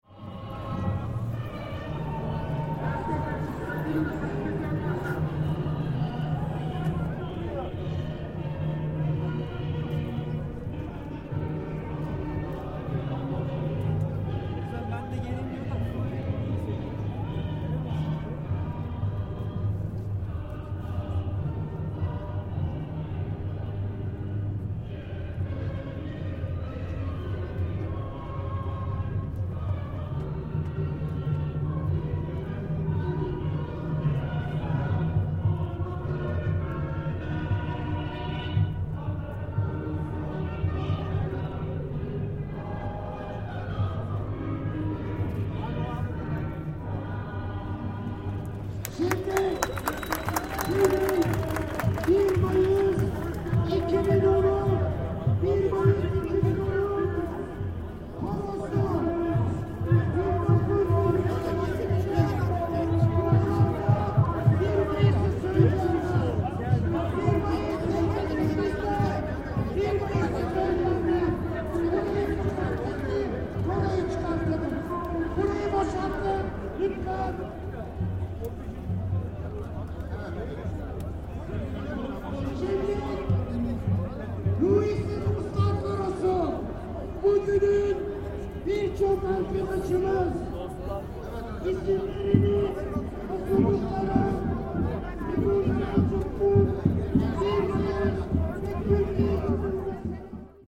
The national anthem Istiklal marsi was played from the loudspeakers just before the introduction of the Friends of Ruhi Su choir next in the programme: